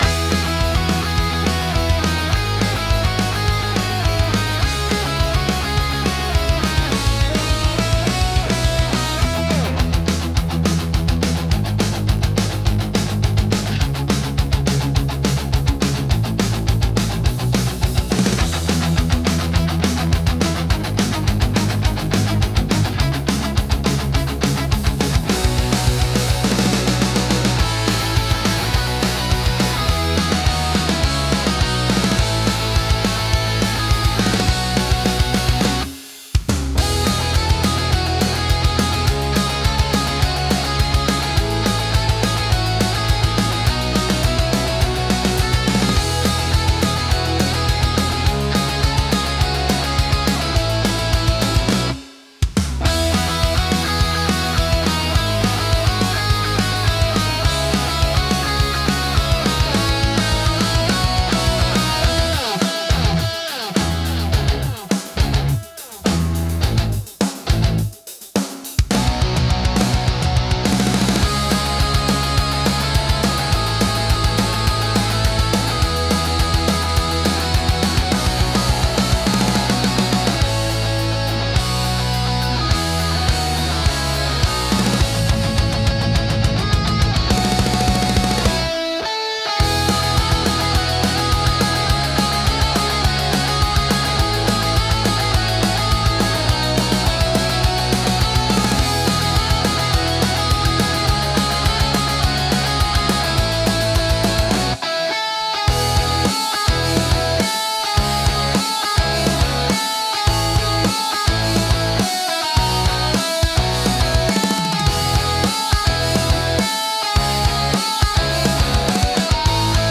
生々しさと疾走感を併せ持つ力強いジャンルです。